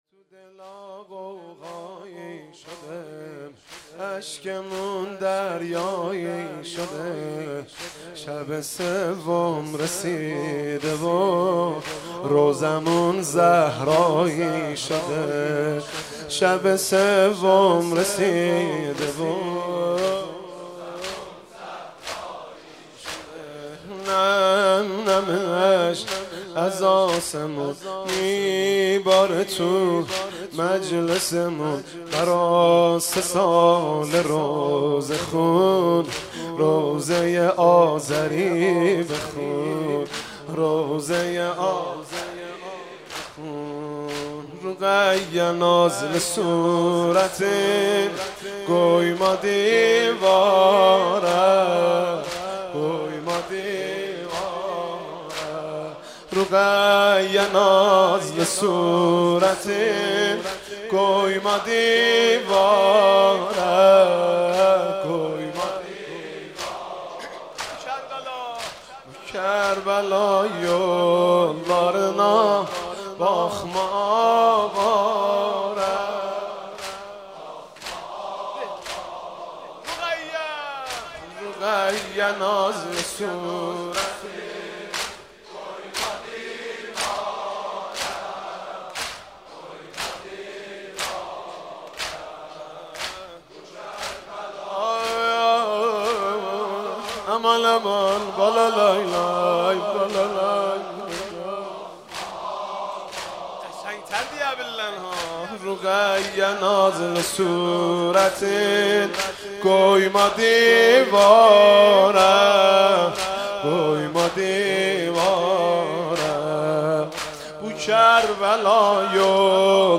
----------------------------------------------------- لینک سرور لینک آپارات ----------------------------------------------------- مداحی آذری: رقیه نازلی صورتین قویما دیواره..